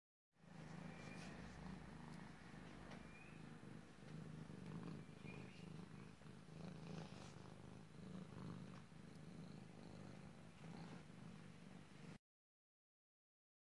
猫的呼噜声XY
描述：这是我的猫咕噜声记录在Roland R26 winth XY mics上
标签： 模拟 发出叫声 CA 可爱 猫的声音 呼噜声
声道立体声